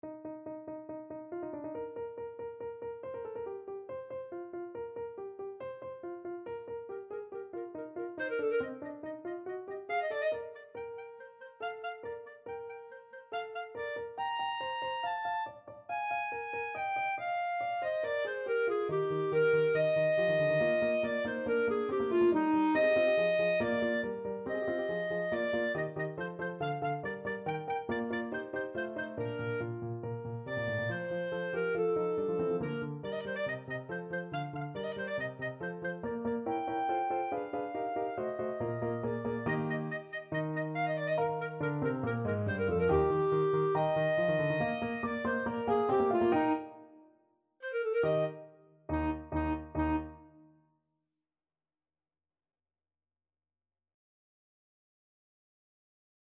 Clarinet
Eb major (Sounding Pitch) F major (Clarinet in Bb) (View more Eb major Music for Clarinet )
= 140 Allegro (View more music marked Allegro)
4/4 (View more 4/4 Music)
Eb5-Bb6
Classical (View more Classical Clarinet Music)